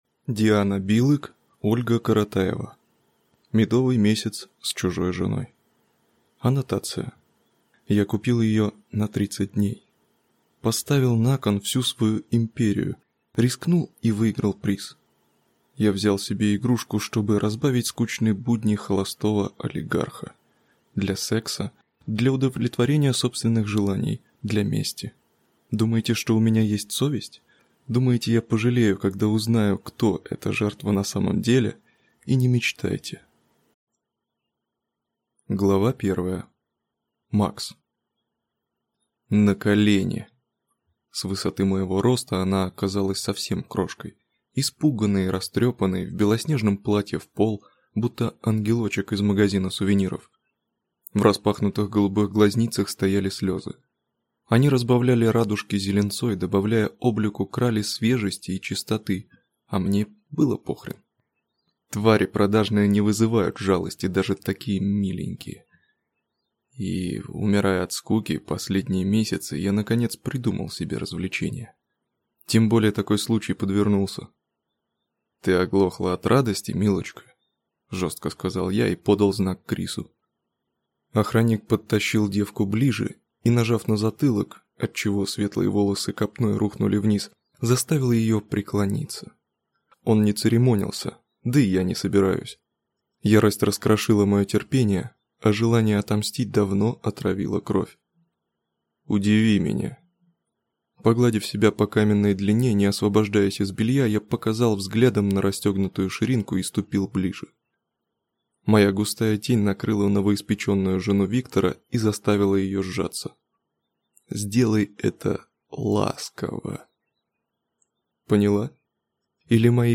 Аудиокнига Медовый месяц с чужой женой | Библиотека аудиокниг